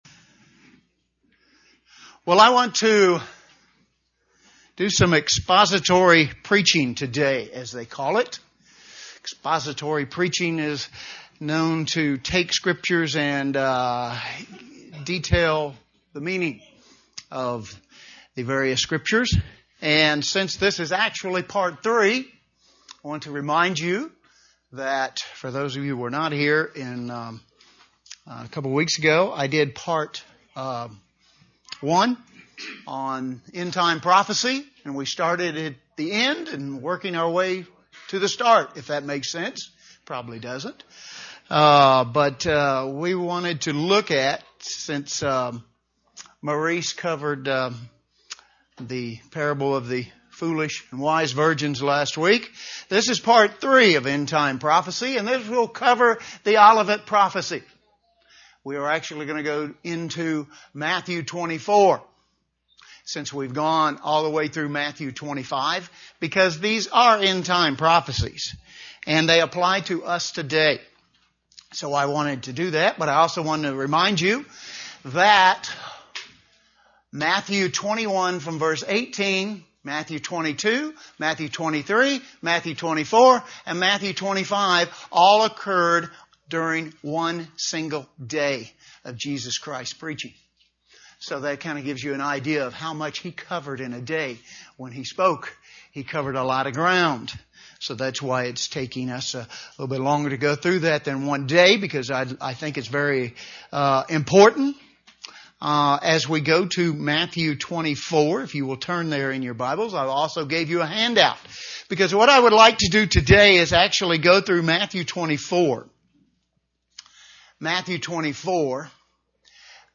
End-time events predicted by Jesus in Matthew 24 - The Olivet Prophecy UCG Sermon Transcript This transcript was generated by AI and may contain errors.